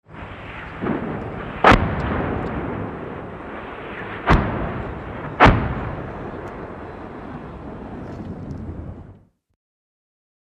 HELICOPTER BELL AH-1 SEA COBRA: EXT: Rocket fires & strikes.